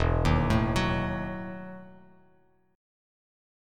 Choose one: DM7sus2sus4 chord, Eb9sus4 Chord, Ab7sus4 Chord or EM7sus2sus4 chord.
EM7sus2sus4 chord